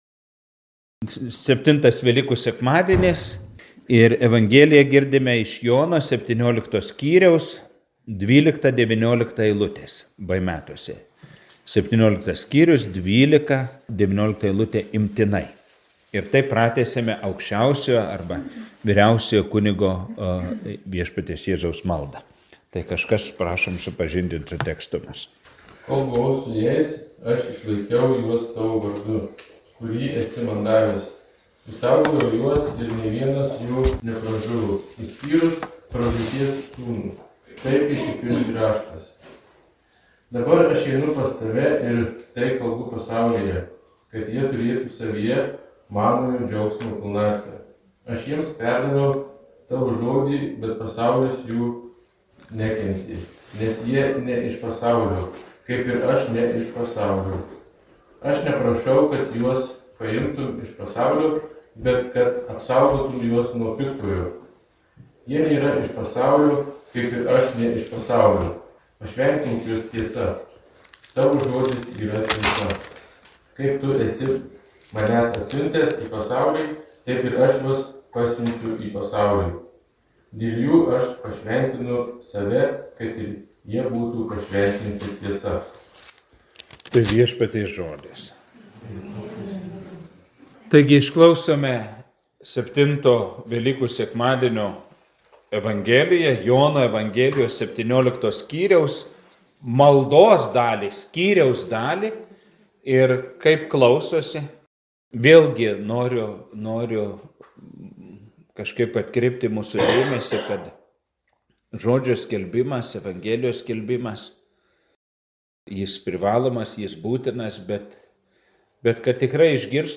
Evangelijos tekstas Jn 17, 12-19 Paskaitos audioįrašas